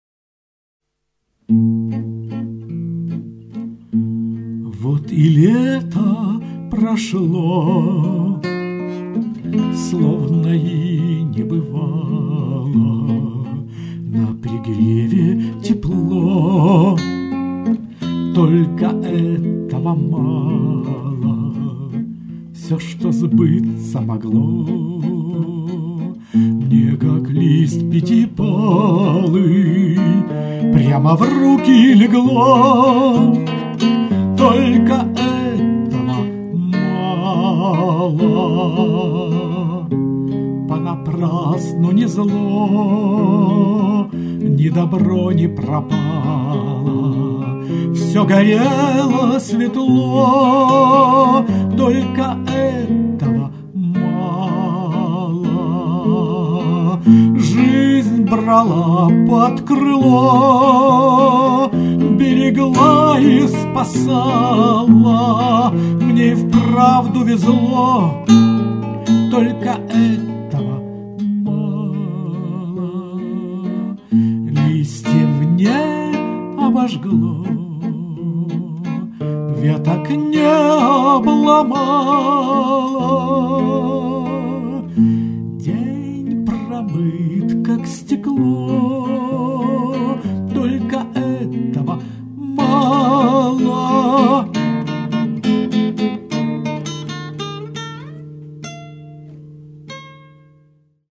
Наверное, это называется-Романс))))